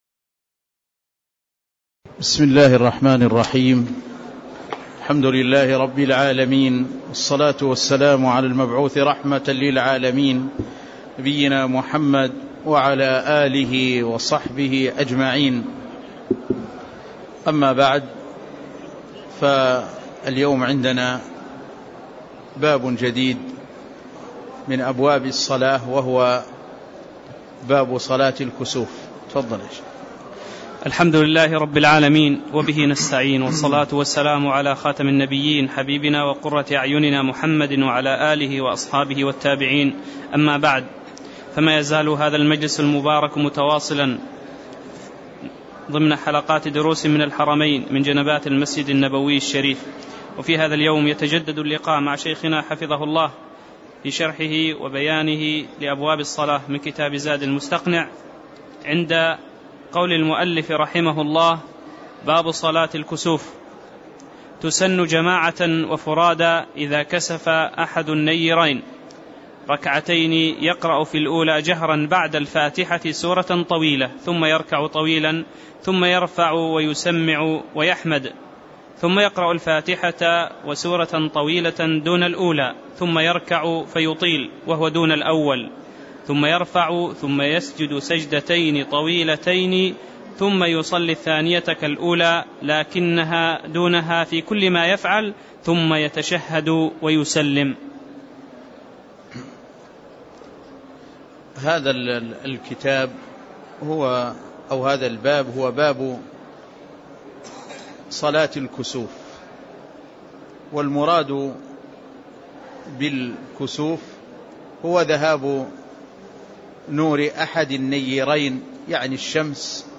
تاريخ النشر ١٣ ربيع الأول ١٤٣٦ هـ المكان: المسجد النبوي الشيخ